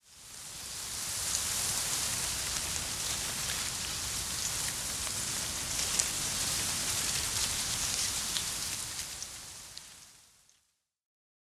windtree_3.ogg